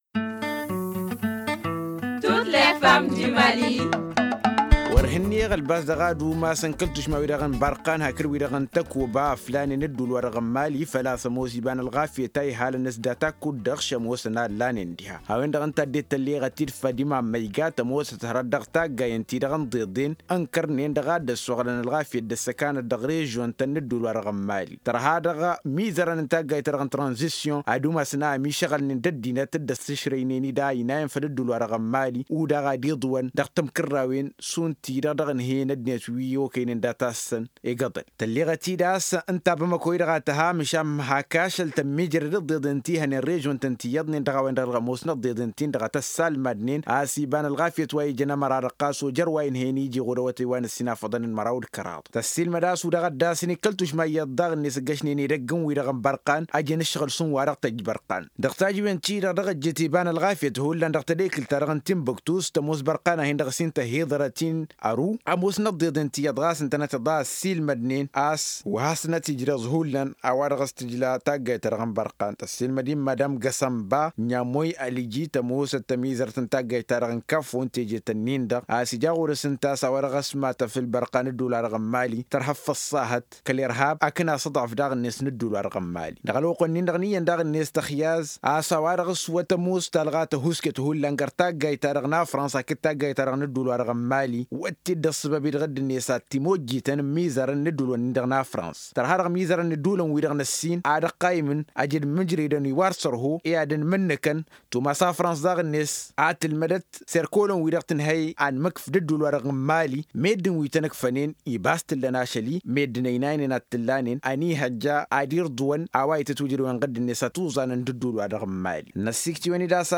Selon ces dernières, le départ de Barkhane et Takuba risque d’accentuer l’insécurité dans le nord du pays. Elles réagissent dans ce magazine dédiéé aux femmes du Mali.